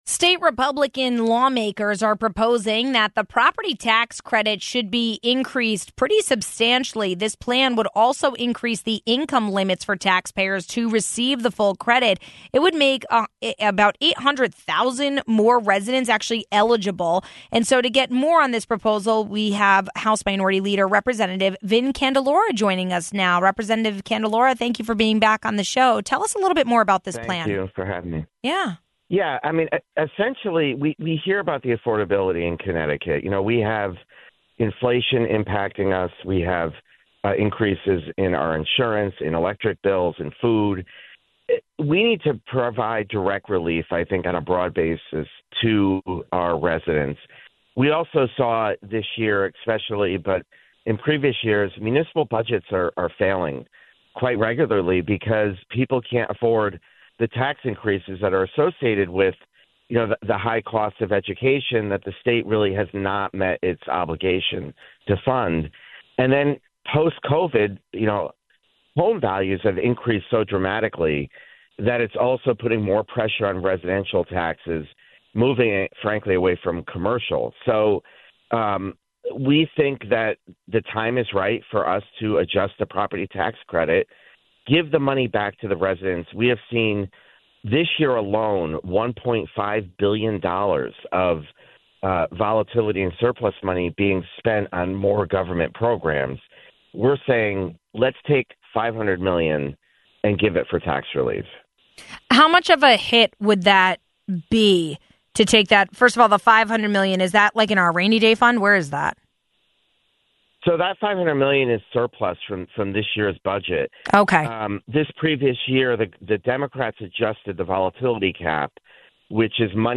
For more on this proposal, we spoke to House Minority Leader, Representative Vin Candelora.